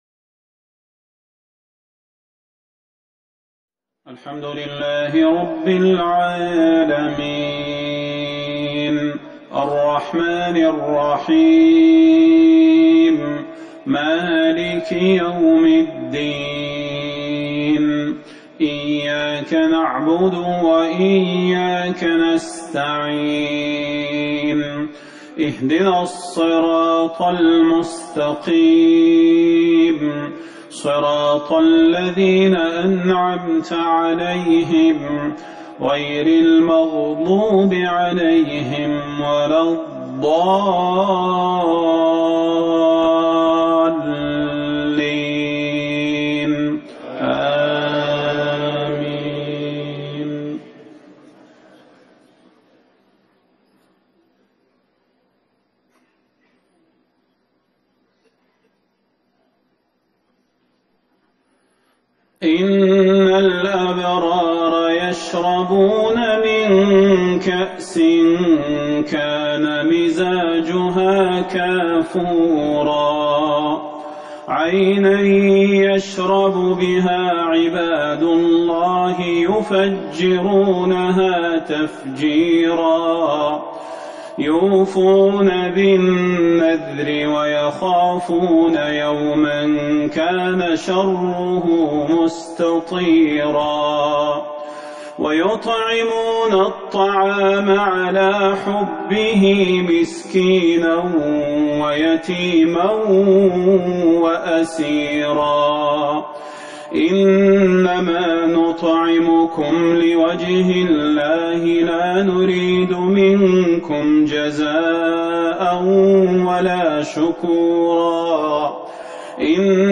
صلاة المغرب 13 جمادى الاولى 1441 من سورة الانسان | Maghrib Prayer 1-1-2020 from Surat al-Insane > 1441 🕌 > الفروض - تلاوات الحرمين